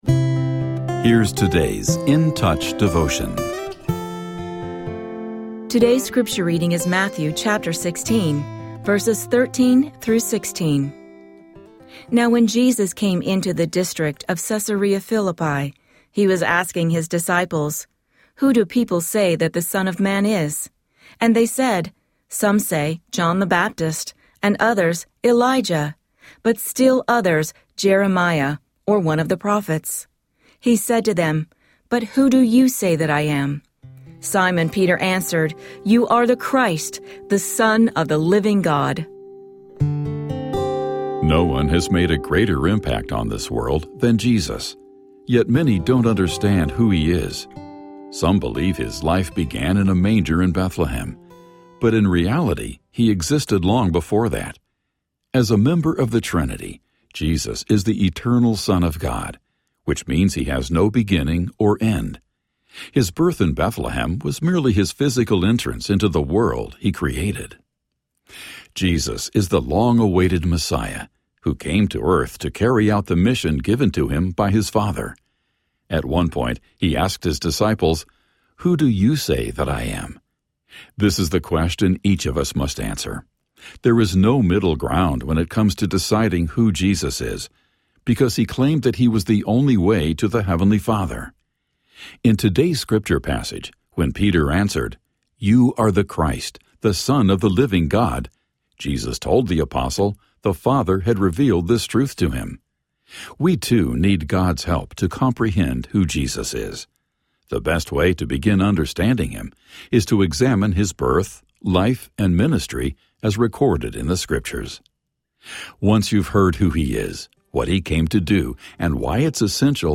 Daily audio devotional from Charles Stanley’s In Touch Ministries.